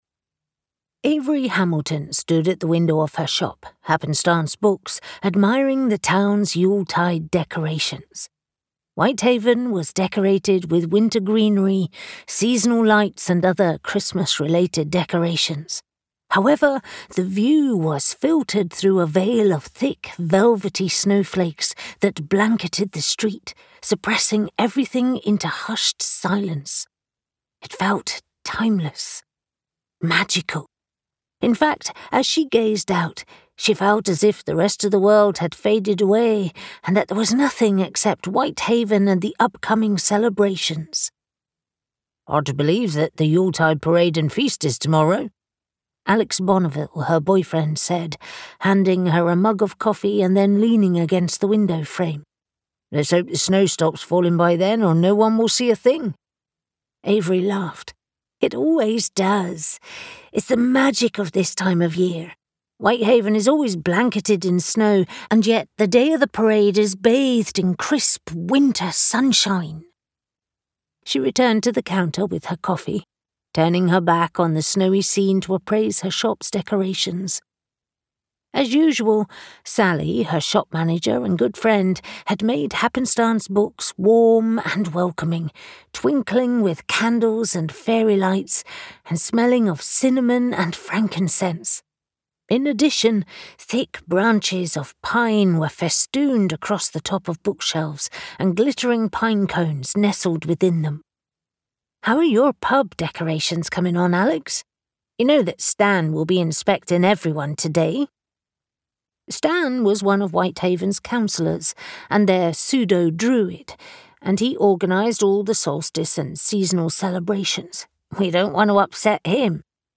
TJ Green Author Midwinter Magic Audiobook - TJ Green Author